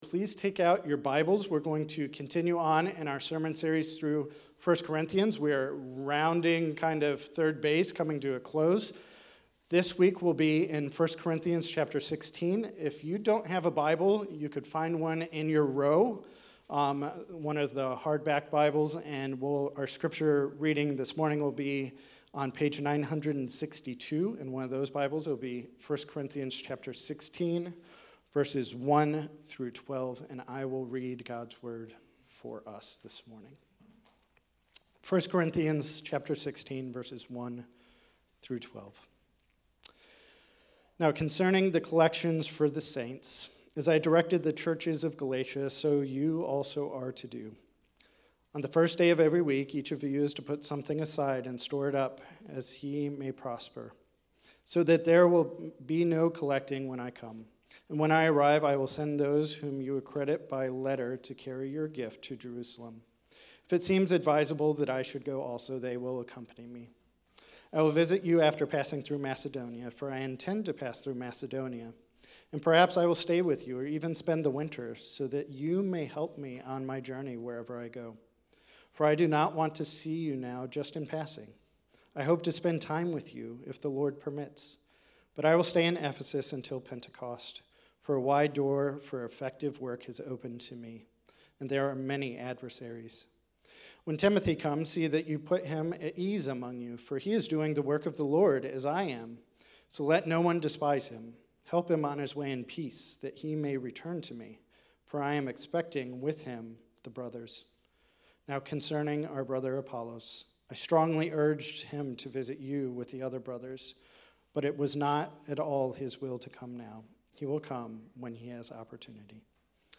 Sermons - Grace City Church of the Northeast